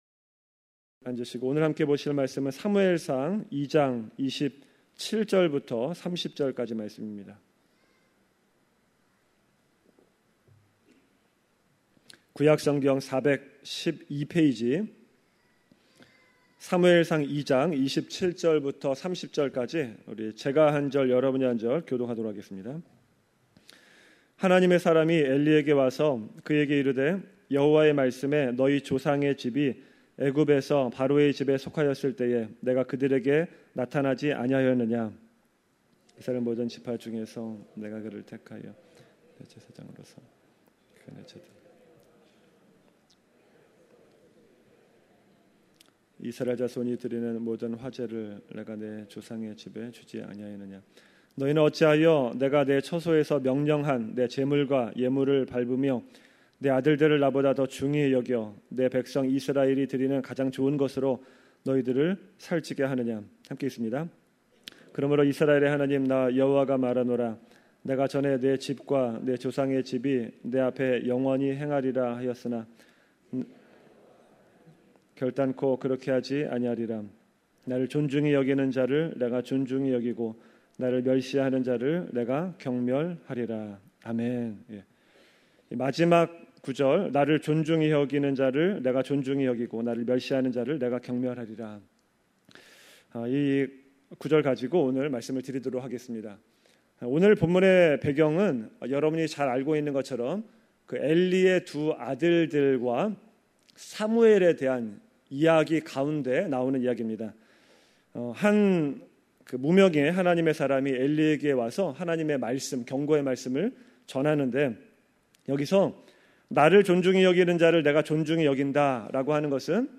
2019년 금요설교